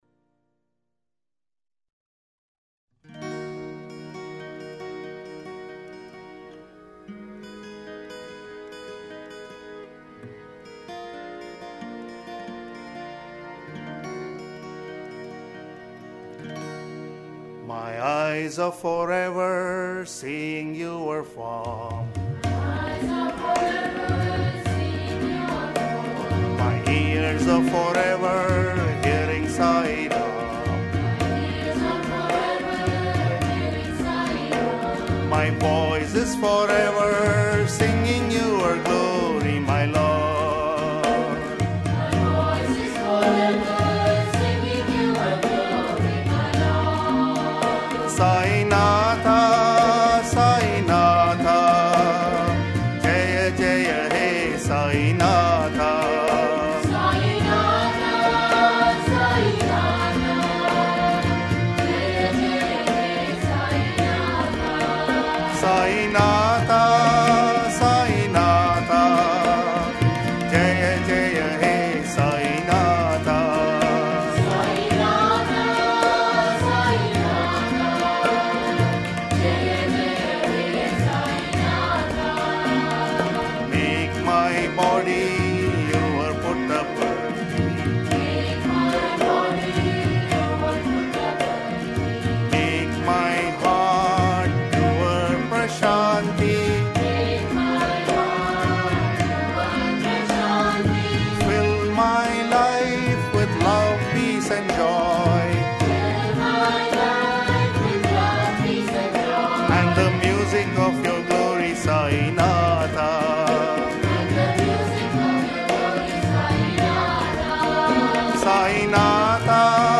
Minor (Natabhairavi)
8 Beat / Keherwa / Adi
Medium Slow